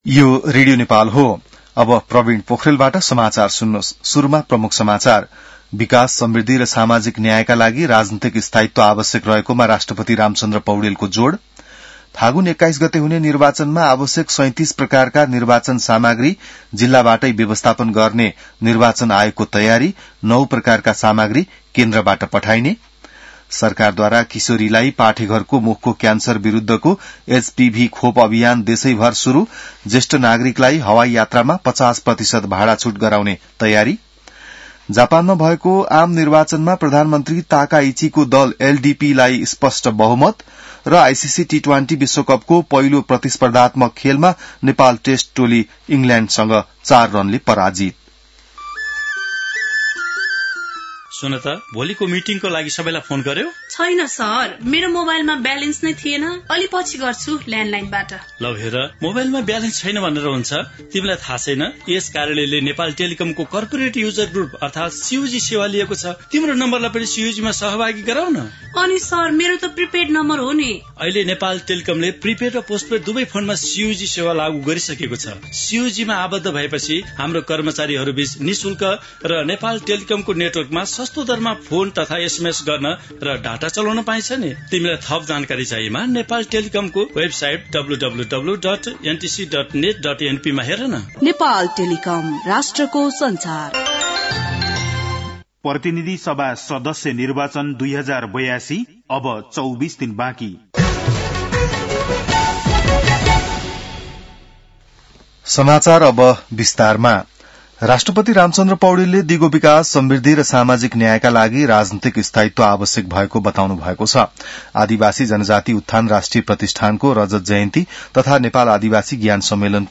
बिहान ७ बजेको नेपाली समाचार : २६ माघ , २०८२